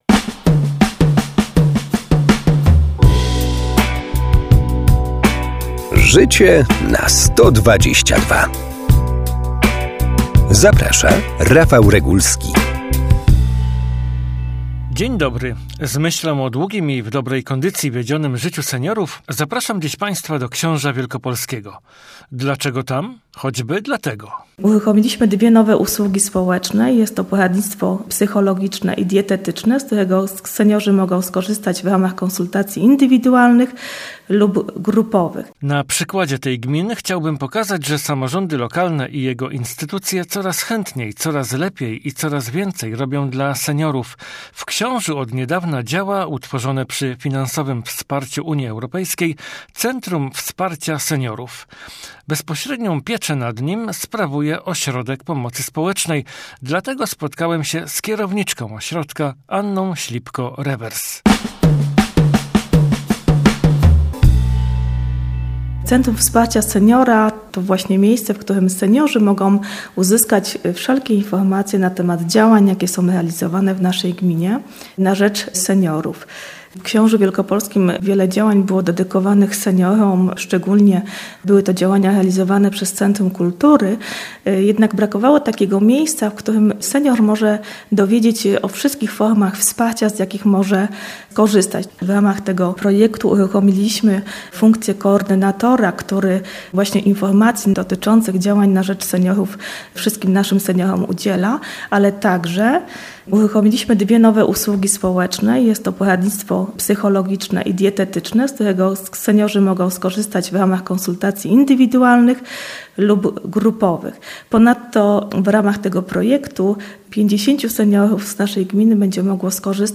Wizyta w działającym od niedawna Centrum Wsparcia Seniora w Książu Wlkp. Rozmowa